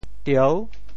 召 部首拼音 部首 口 总笔划 5 部外笔划 2 普通话 zhào shào 潮州发音 潮州 dieu6 文 潮阳 diao6 澄海 diou6 揭阳 diao6 饶平 diao6 汕头 diao6 中文解释 潮州 dieu6 文 对应普通话: zhào ①召唤：～集 | ～见。